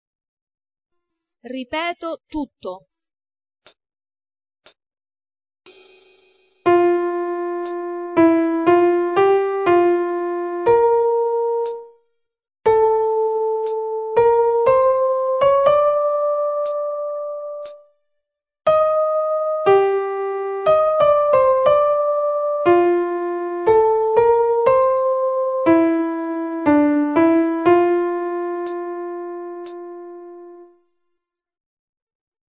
Richiedi gratuitamente il Dettato di prova (su 8 misure)
con modulazione divergente